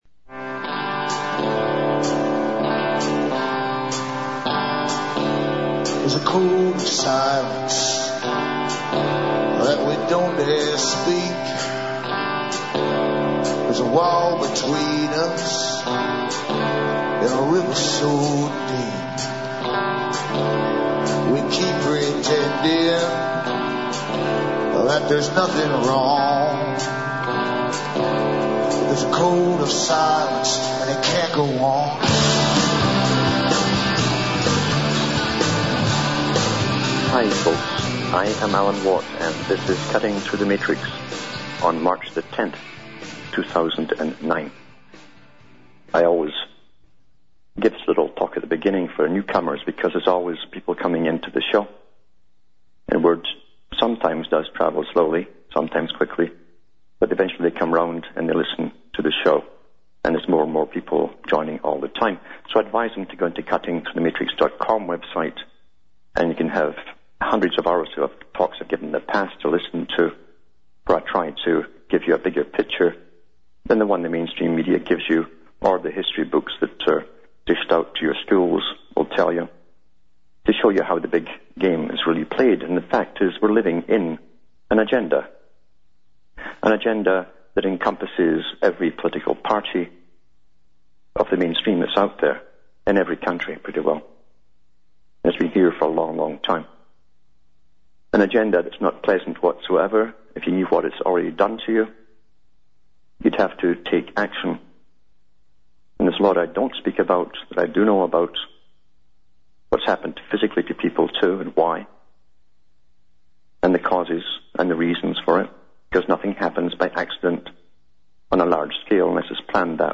LIVE on RBN